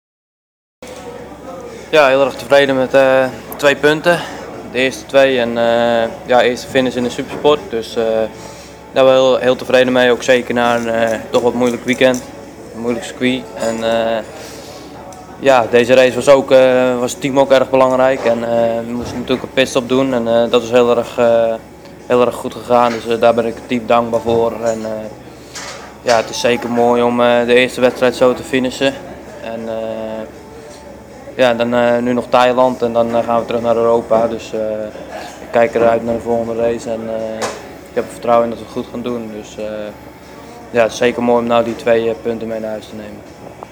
Na afloop van de race zochten we de twee Nederlanders op en vroegen hun naar een eerste reactie.